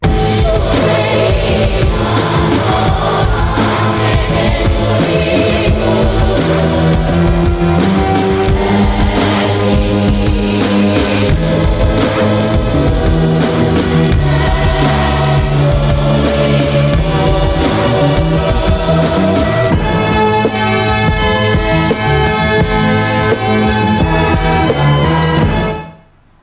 Sarjan tunnusmusiikki: